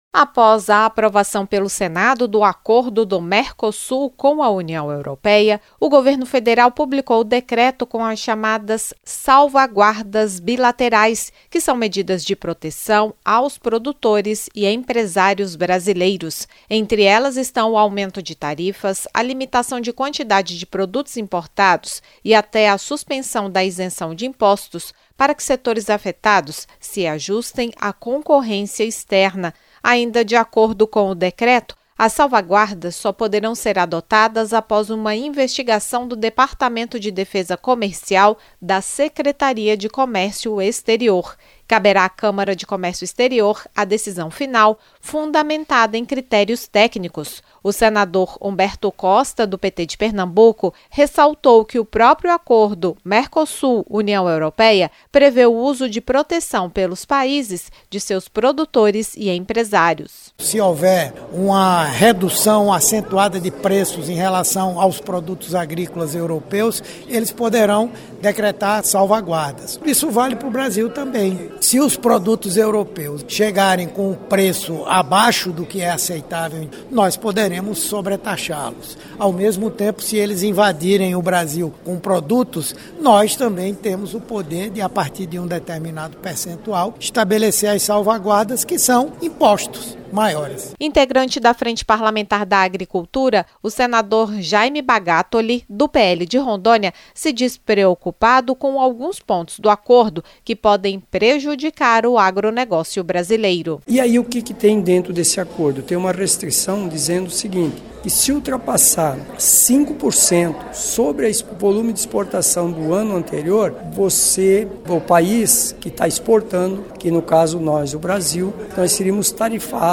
O senador Humberto Costa (PT-PE) explicou que entre as proteções previstas estão a suspensão da isenção das tarifas e até cotas de importação quando os produtos europeus forem mais baratos ou em quantidades que prejudiquem o mercado nacional. Já o senador Jaime Bagattoli (PL-RO) citou preocupações do agro com salvaguardas europeias.